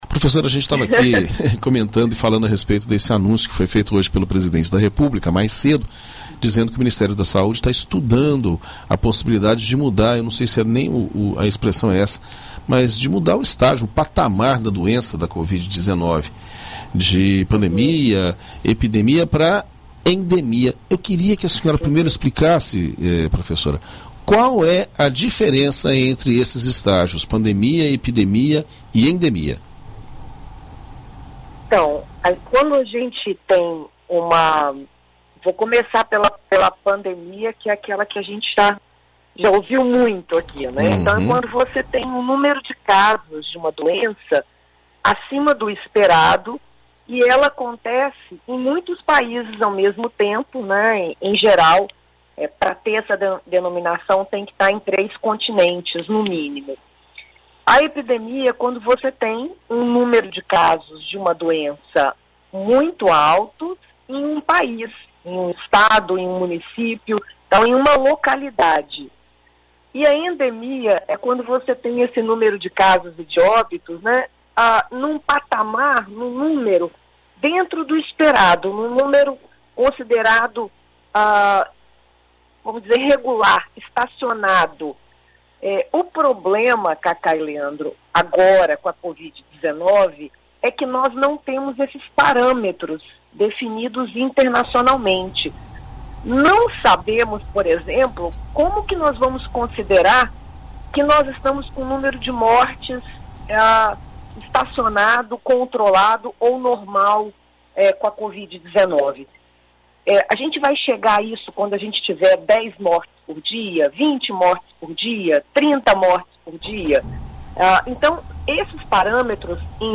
Em entrevista à BandNews FM Espírito Santo nesta quinta-feira (03)